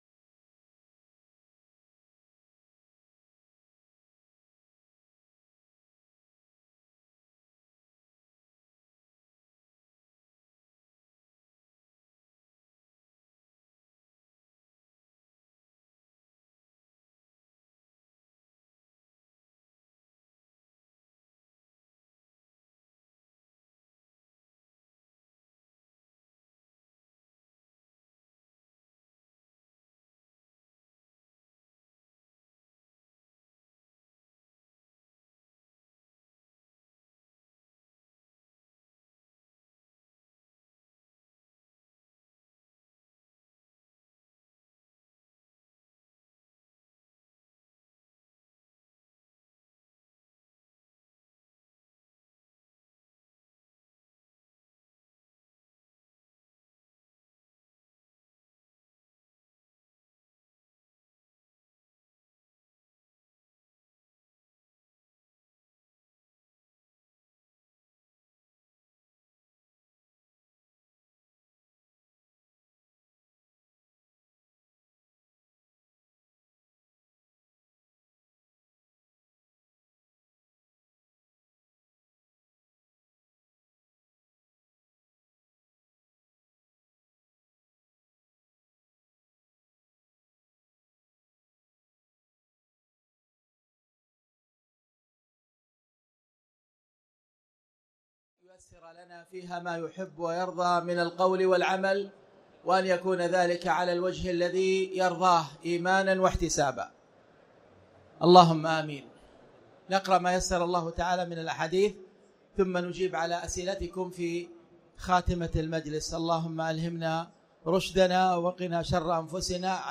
تاريخ النشر ٢٤ رمضان ١٤٣٩ هـ المكان: المسجد الحرام الشيخ: فضيلة الشيخ أ.د. خالد بن عبدالله المصلح فضيلة الشيخ أ.د. خالد بن عبدالله المصلح شرح أحاديث باب صوم التطوع